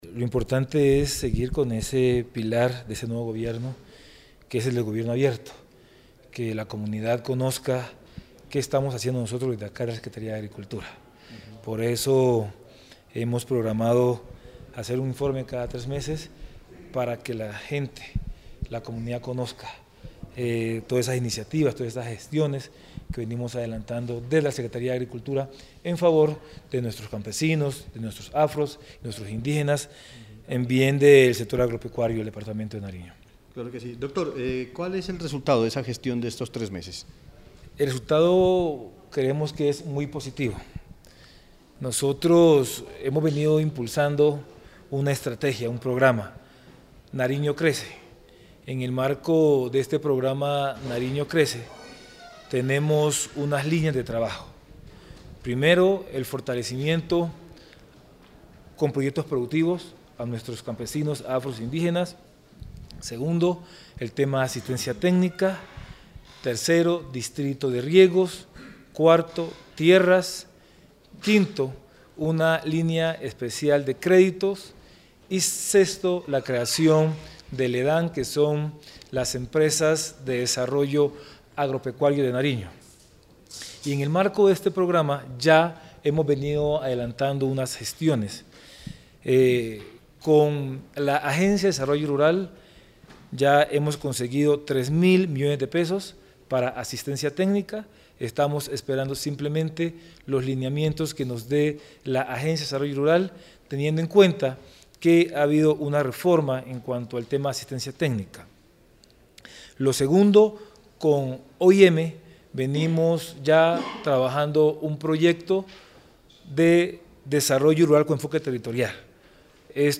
Marco Marinez, secretario de Agricultura de Nariño, presentó un informe detallado de la gestión y el trabajo que desde esta dependencia se adelantó durante los tres primeros meses de 2017.